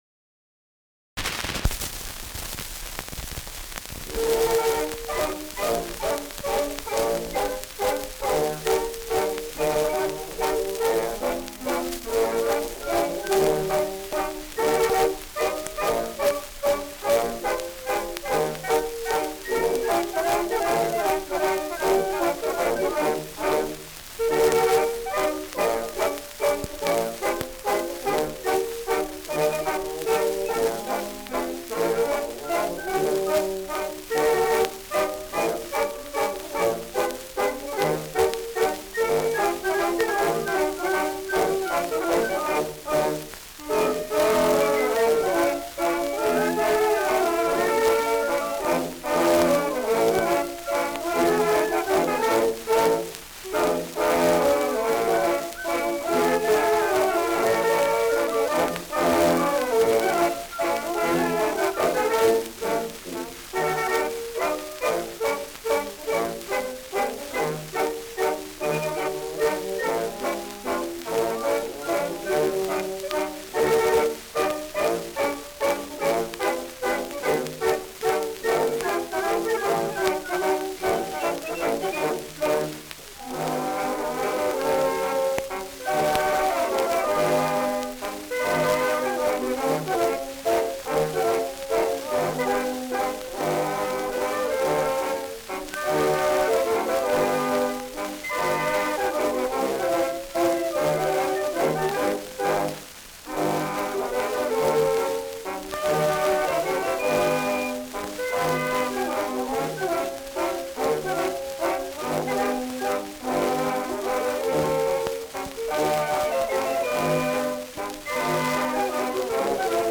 Schellackplatte
Tonrille: Kratzer 2-5 / 8-11 Uhr Stärker
präsentes Rauschen